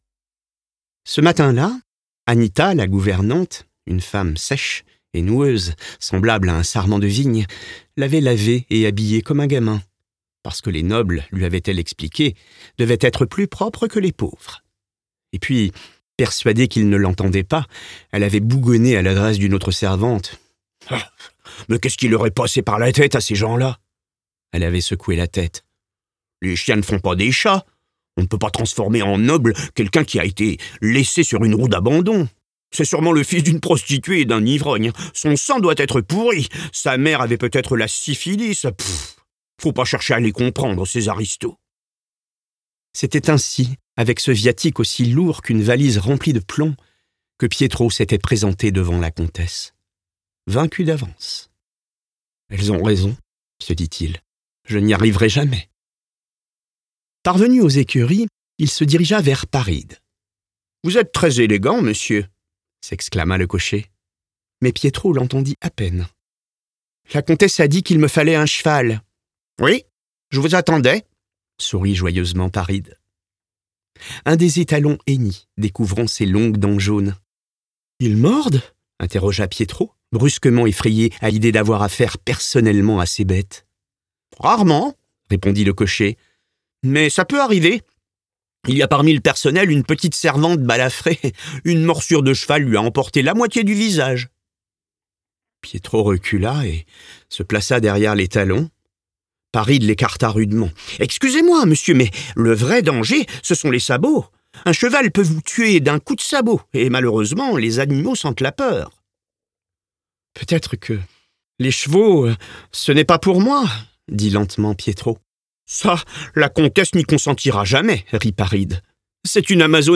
LIVRE AUDIO - Mamma Roma (Luca Di Fulvio)
32 - 64 ans - Baryton